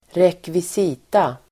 Ladda ner uttalet
Uttal: [²rekvis'i:ta]